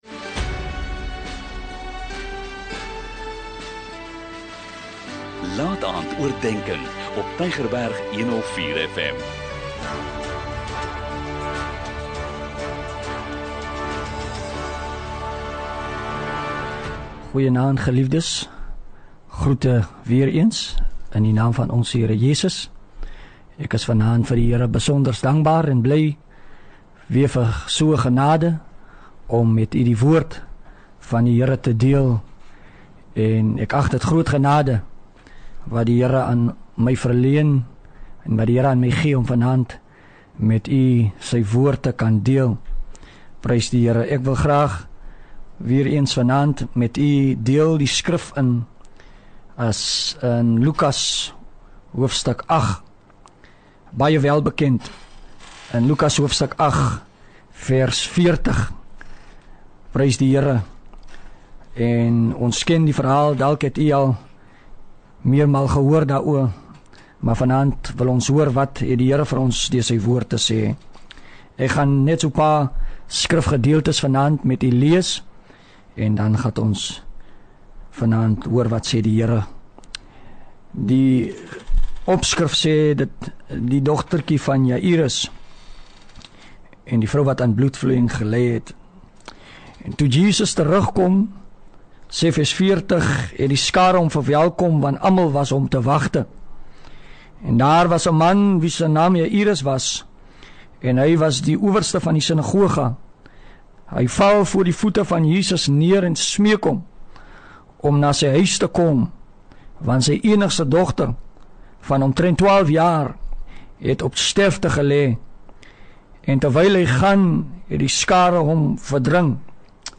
'n Kort bemoedigende boodskap, elke Sondagaand om 20:45, aangebied deur verskeie predikers.